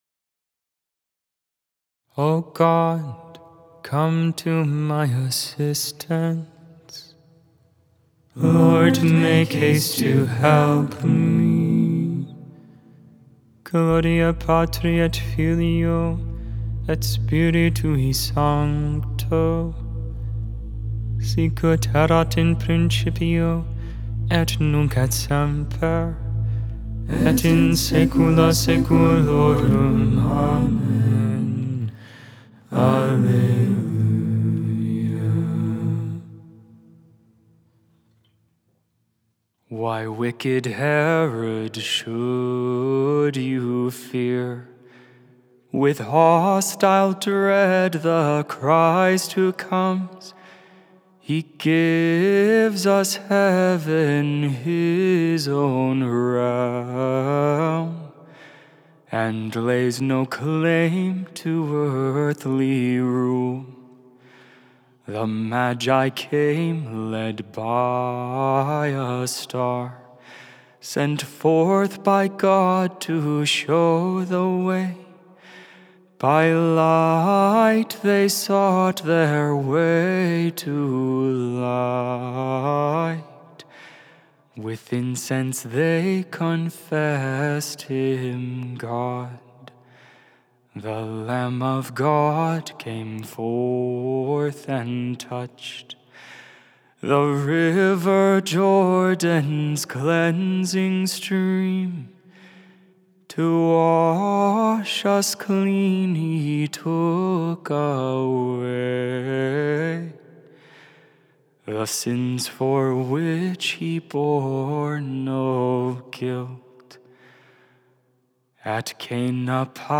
1.8.25 Vespers, Wednesday Evening Prayer of the Liturgy of the Hours